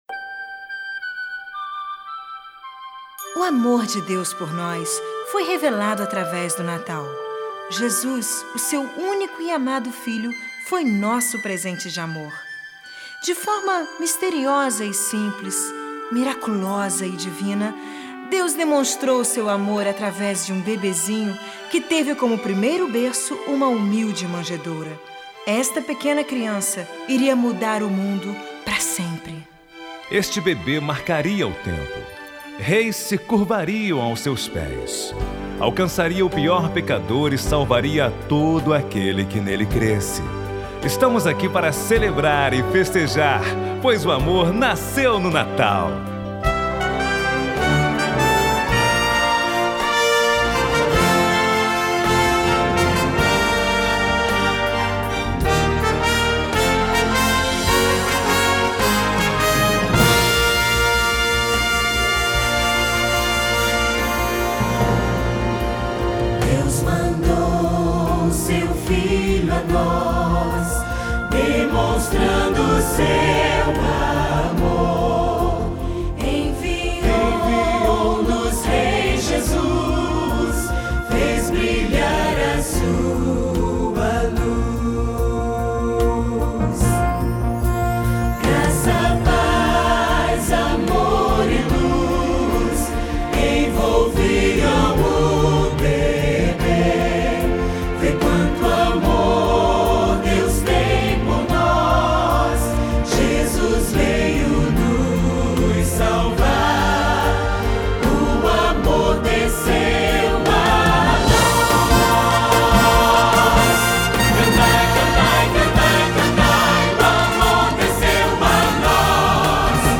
narração
solo infantil